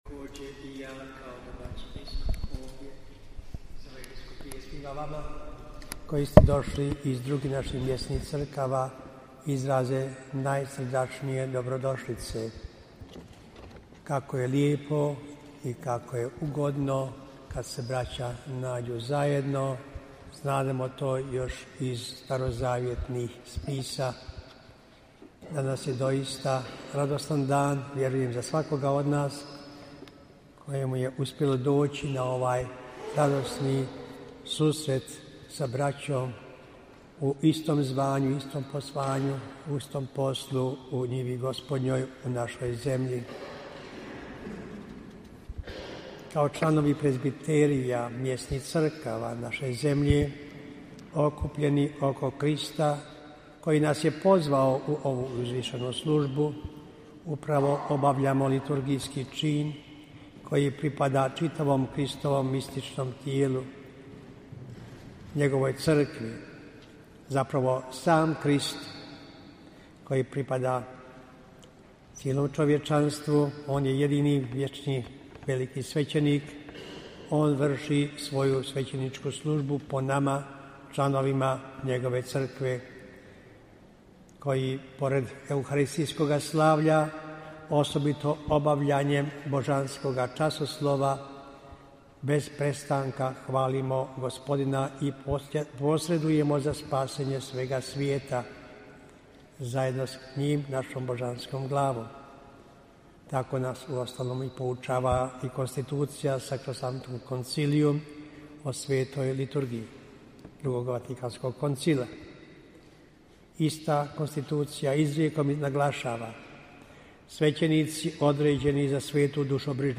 AUDIO: MEDITACIJA BISKUPA KOMARICE NA 5. SUSRETU SVEĆENIKA BOSNE I HERCEGOVINE U LIVNU - BANJOLUČKA BISKUPIJA
Susret svećenika u franjevačkoj samostanskoj crkvi sv. Petra i Pavla u Livnu.
Nakon kratkog čitanja biskup Komarica je izrekao sljedeću meditaciju: